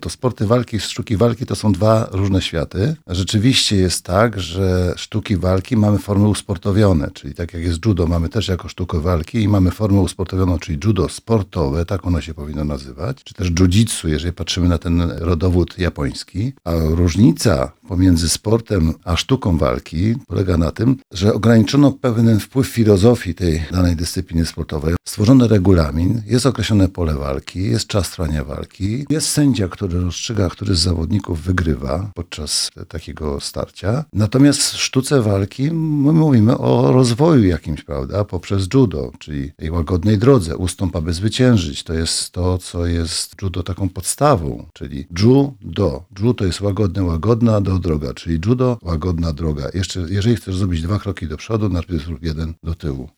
Różnicę między tymi pojęciami wyjaśnia dr Rafał Kubacki, dwukrotny mistrz świata w tej dyscyplinie, a dziś wykładowca Akademii Wychowania Fizycznego we Wrocławiu
Cała rozmowa z dr. Rafałem Kubackim w audycji „Sportowy kwadrans” dziś o 15:30!